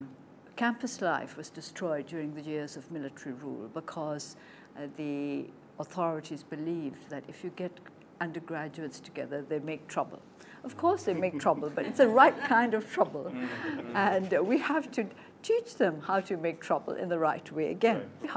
2. 昂山素姬對仰光大學恢復正常校園生活的發言片段